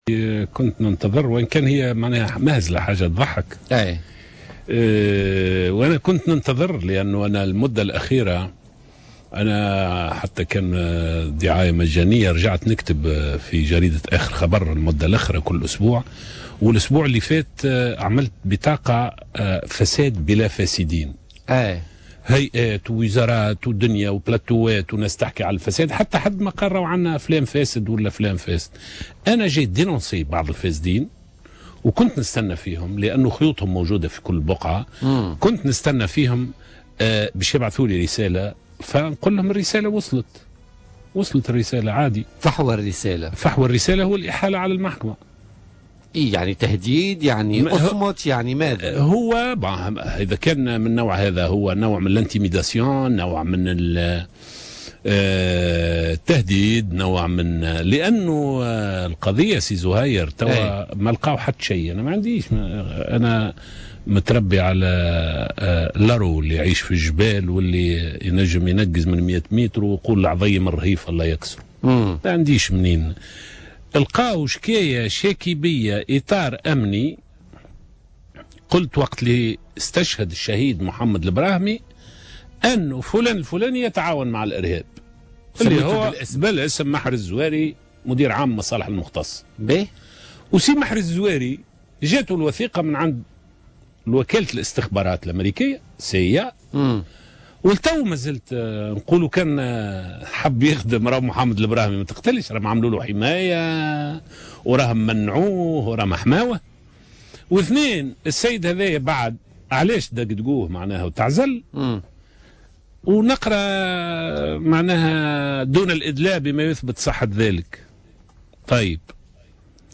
وتساءل العكرمي، ضيف برنامج "بوليتيكا" عن المغزى من إثارة قضية تعود إلى سنة 2013، في هذا التوقيت بالذات. وأوضح أن ذلك يأتي كردّة فعل على كتاباته الأخيرة حول الفساد والفاسدين، مضيفا أن الرسالة وصلت وأنه لن يتراجع إلى الوراء.